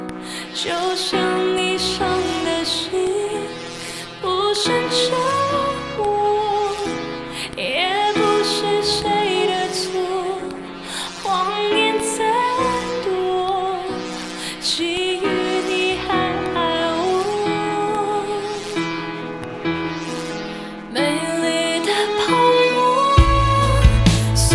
female_wm.mp3